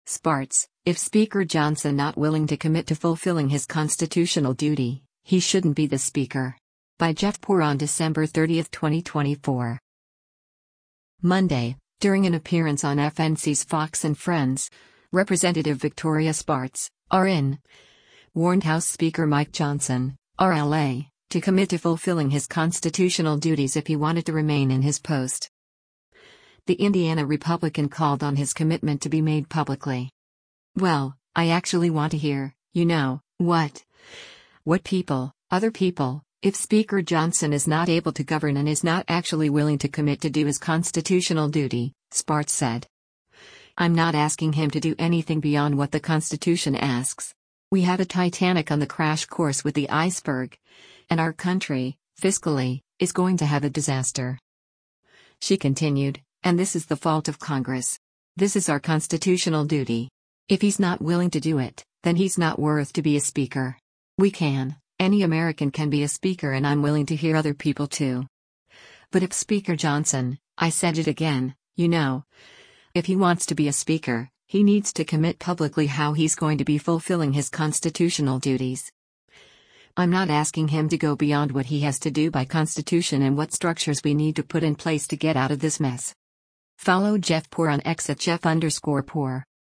Monday, during an appearance on FNC’s “Fox & Friends,” Rep. Victoria Spartz (R-IN) warned House Speaker Mike Johnson (R-LA) to commit to fulfilling his constitutional duties if he wanted to remain in his post.